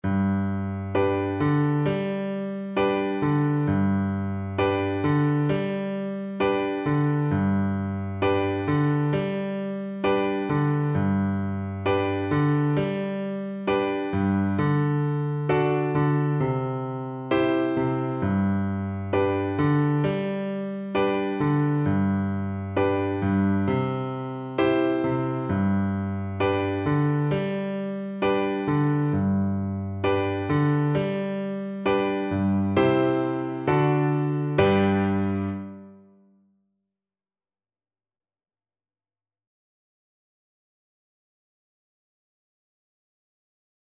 Flute
Traditional Music of unknown author.
G major (Sounding Pitch) (View more G major Music for Flute )
4/4 (View more 4/4 Music)
Moderato
A5-G6
Congolese